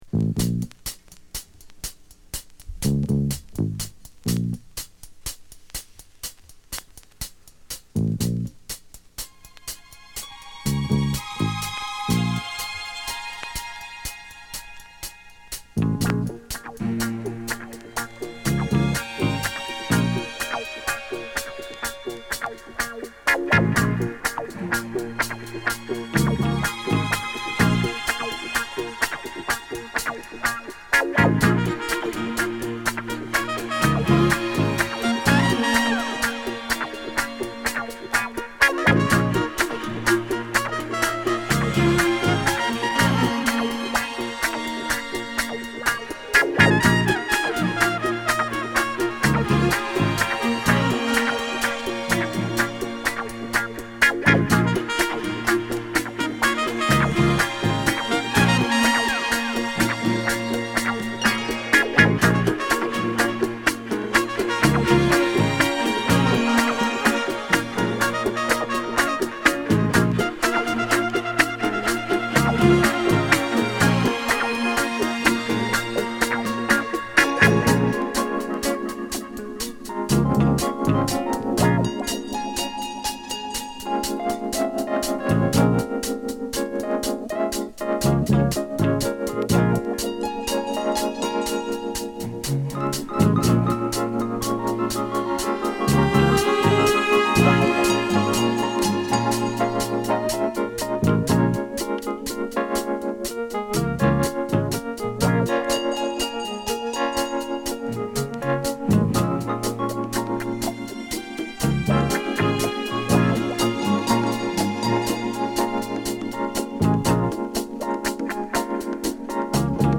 「黒いサイケ」な演奏に、流石のリード＆コーラスワーク！
7インチにはc/wに『インスト』を収録！！